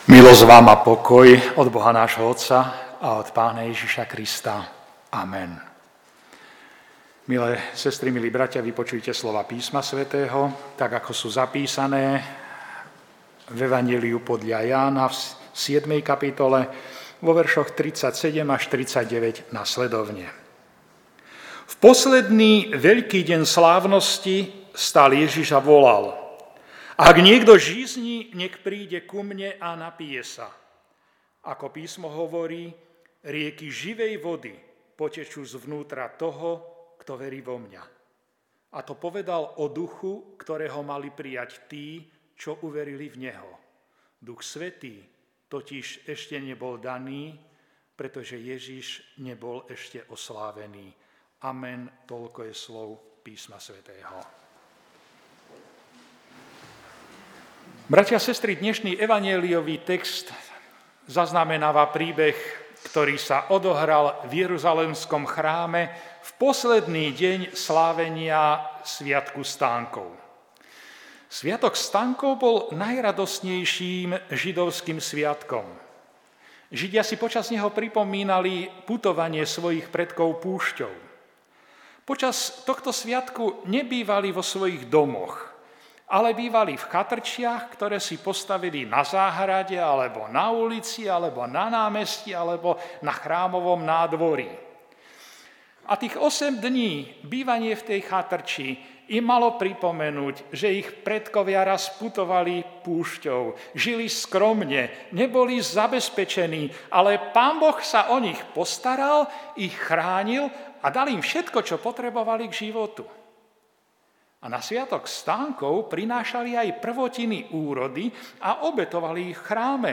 1. slávnosť svätodušná